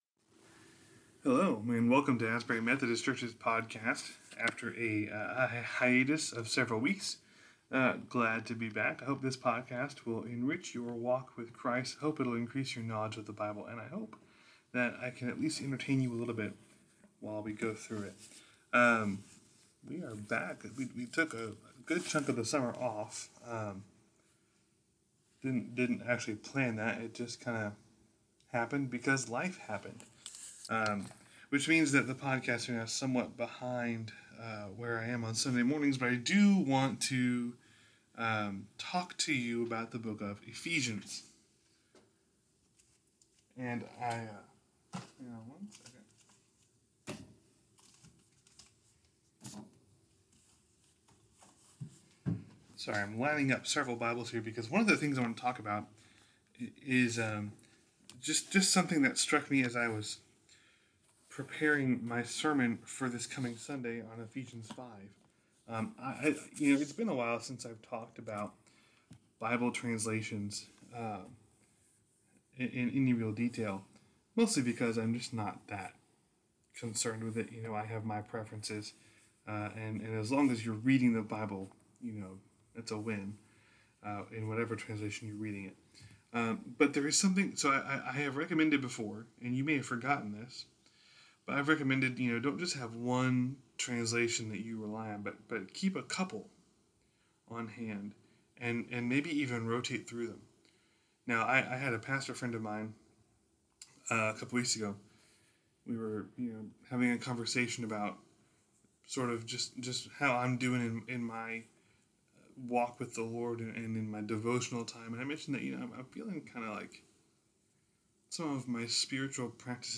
Bible Study for August 7